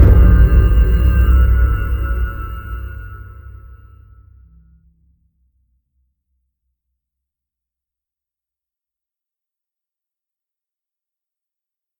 1.21.5 / assets / minecraft / sounds / mob / wither / spawn.ogg